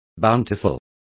Complete with pronunciation of the translation of bountiful.